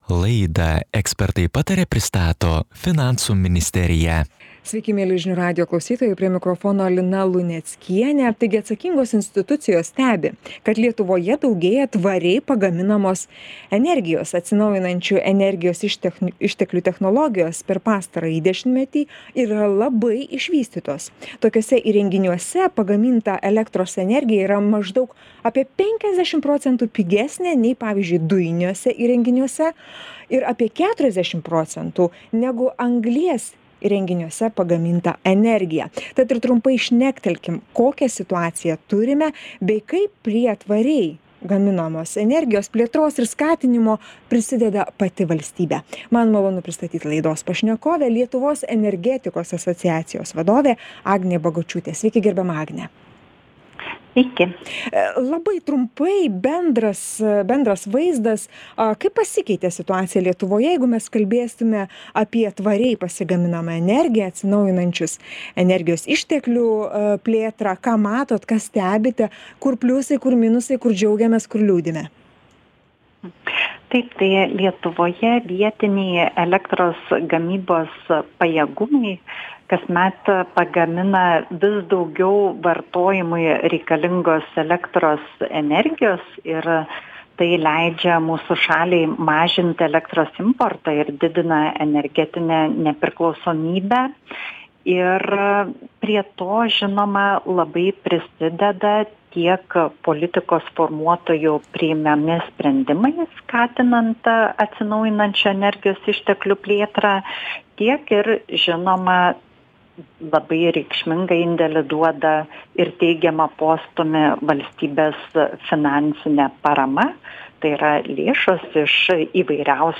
Pokalbis su Lietuvos energetikos agentūros direktore Agne Bagočiute.